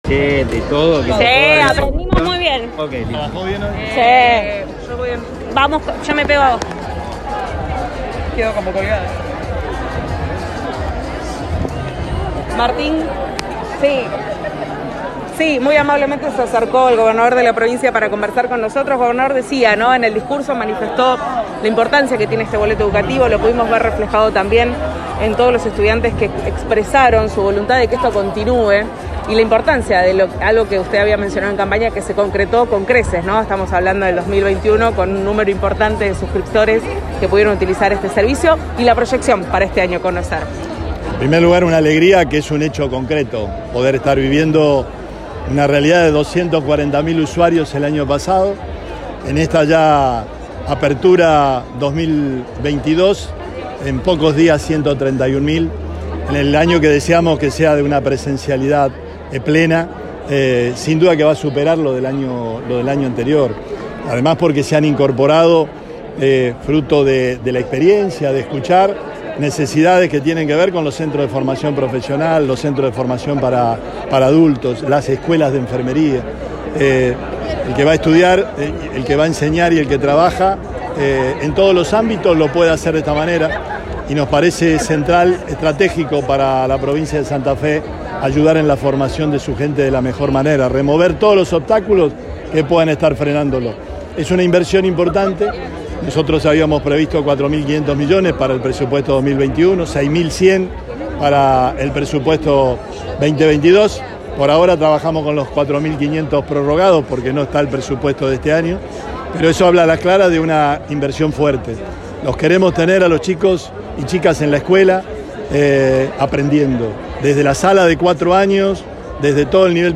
Con motivo del comienzo de un nuevo ciclo lectivo en la provincia, el gobernador Omar Perotti encabezó este jueves por la tarde la presentación del Boleto Educativo Gratuito 2022. El acto se desarrolló en las puertas de la Facultad de Derecho dependiente de la Universidad Nacional de Rosario.